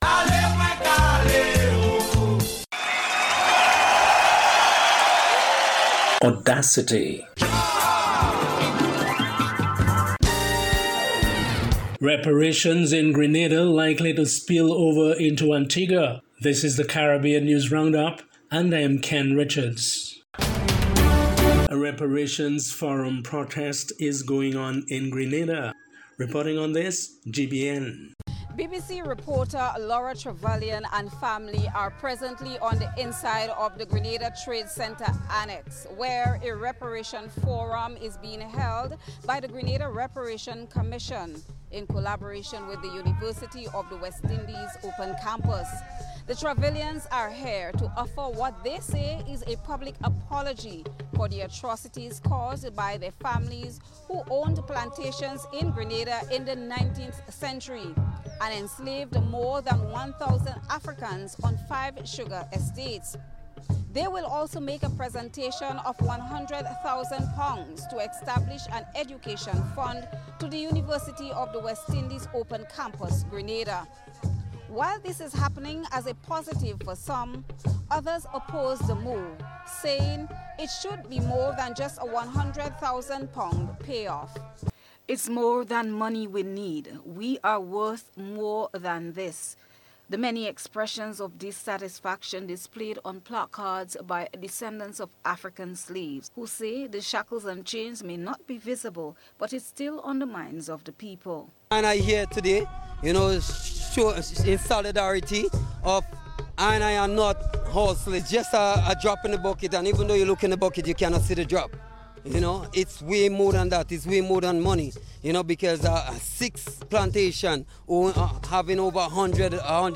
March 1st Wednesday Caribbean News Package